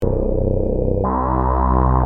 Sequencial Circuits - Prophet 600 47